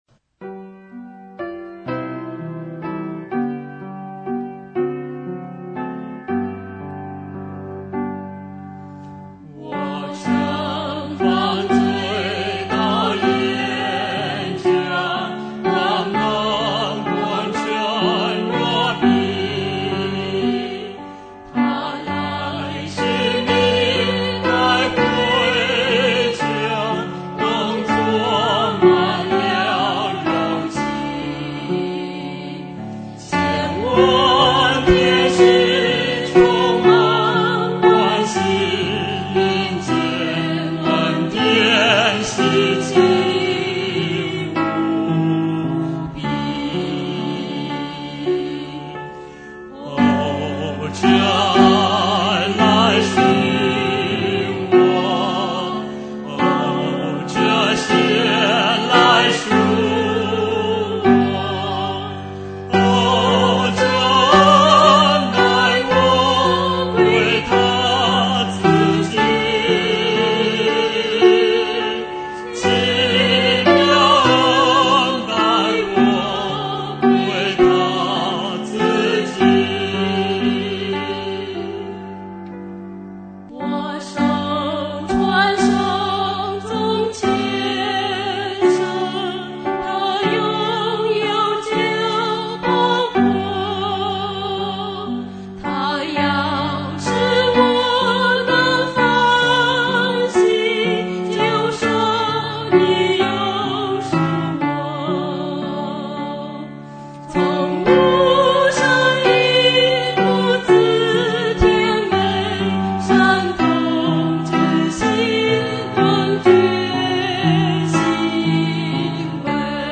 收聽 (鋼琴伴奏。